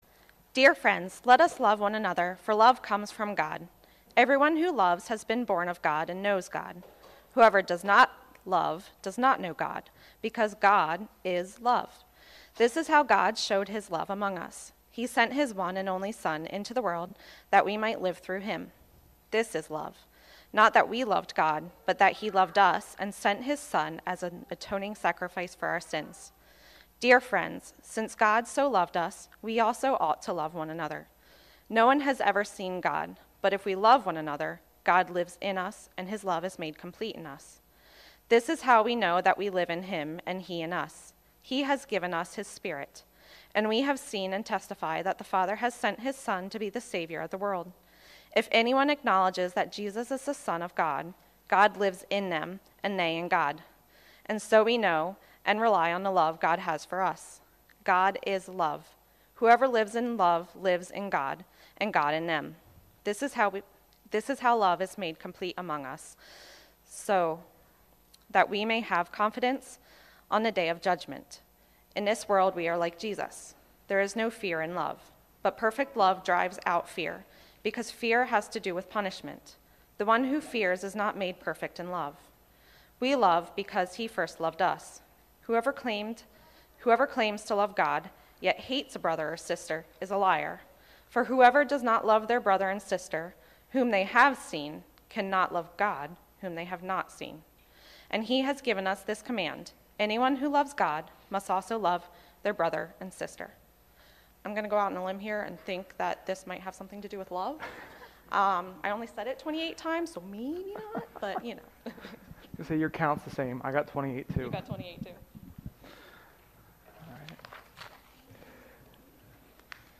Sermon-5.09.21.mp3